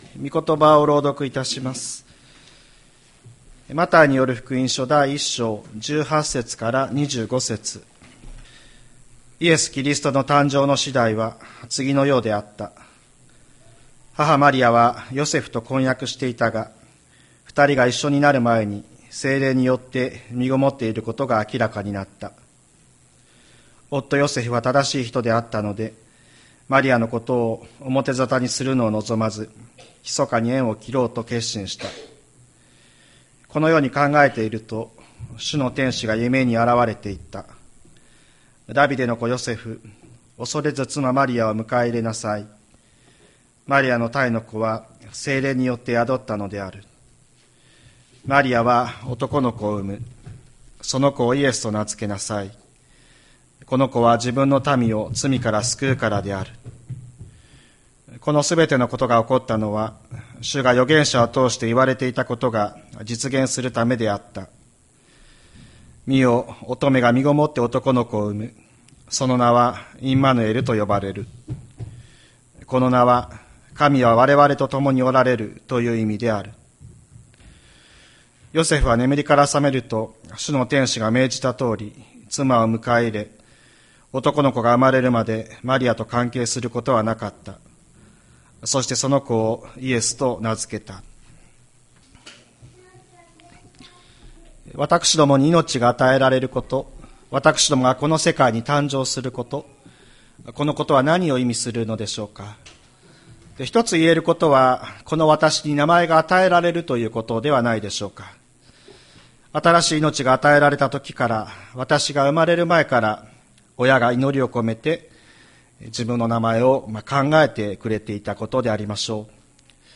2024年02月18日朝の礼拝「イエス、この名のほかに」吹田市千里山のキリスト教会
千里山教会 2024年02月18日の礼拝メッセージ。